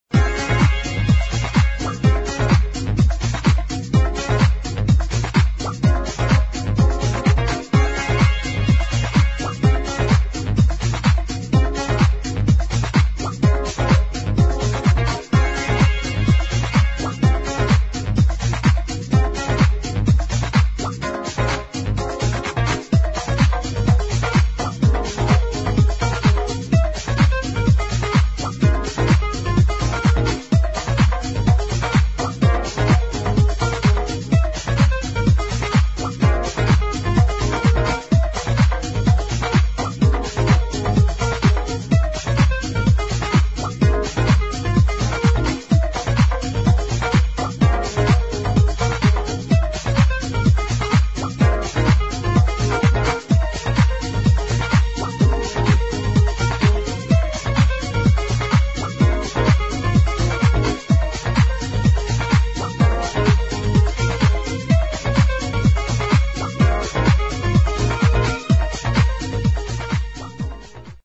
[ HOUSE ]